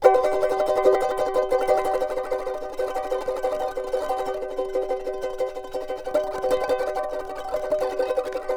CHAR C#MN TR.wav